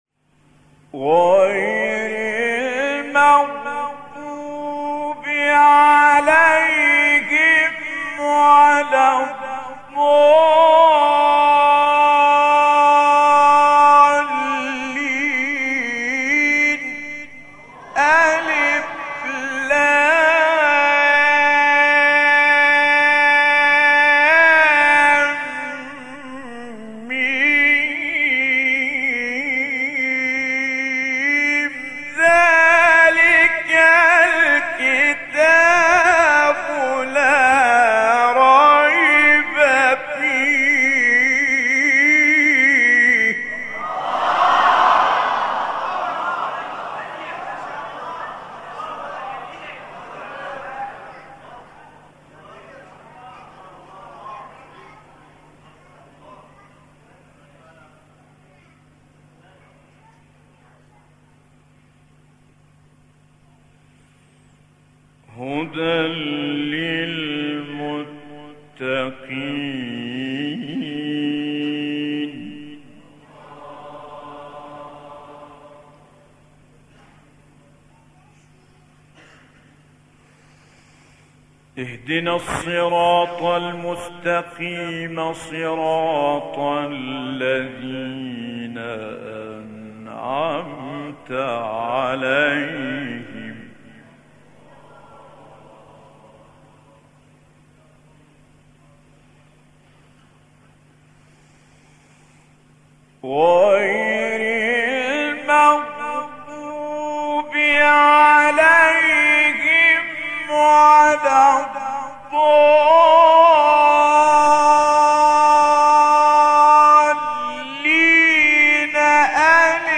سوره : بقره و حمد آیه: (7) – (1-2) استاد : متولی عبدالعال مقام : بیات قبلی بعدی